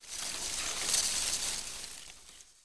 A_leaf2.wav